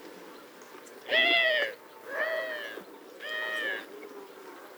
Streptopelia decaocto - Collared dove - Tortora dal collare
- IDENTIFICATION AND BEHAVIOUR: One Collared dove is flying and calling. Zone with a house garden, grazed meadows, wood and rough land. - POSITION: Montiano, LAT.N 42°38'/LONG.E 11°14'- ALTITUDE: +100 m. - VOCALIZATION TYPE: nasal calls. - SEX/AGE: unkown - COMMENT: There are some other doves nearby.